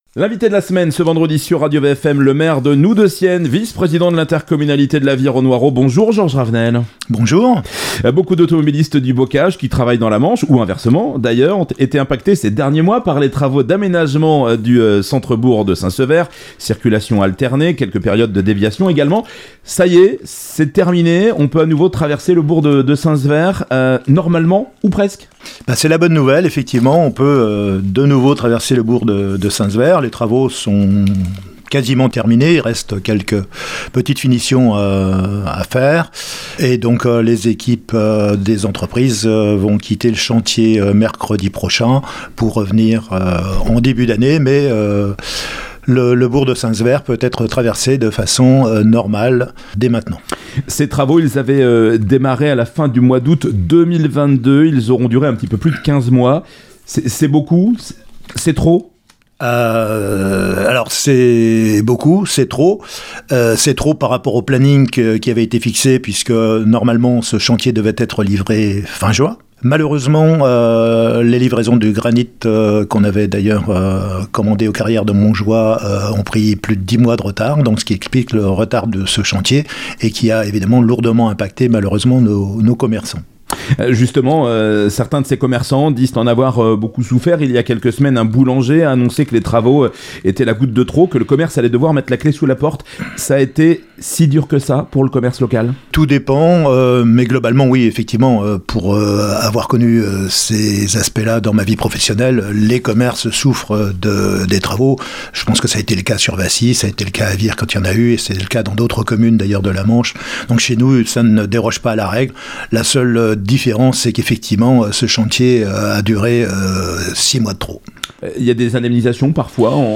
Georges Ravenel Georges Ravenel, maire de Noues de Sienne et vice-président de l'Intercom de la Vire au Noireau